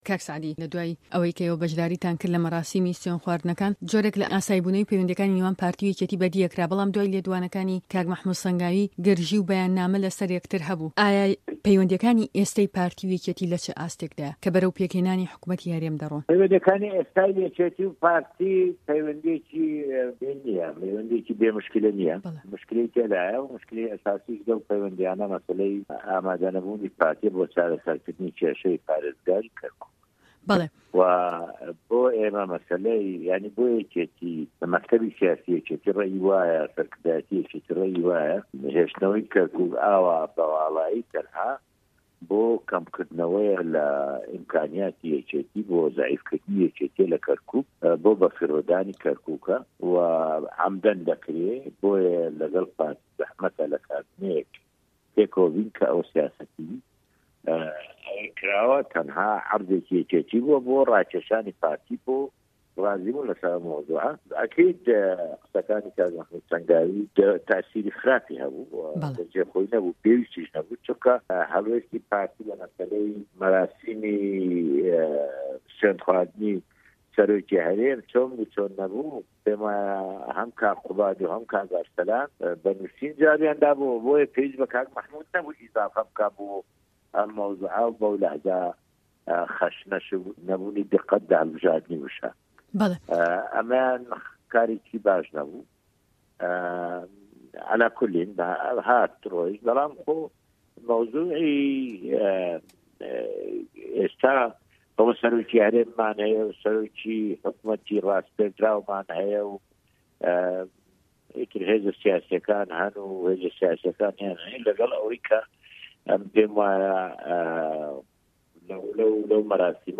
ده‌قی وتووێژه‌كه‌ وه‌ك خۆی: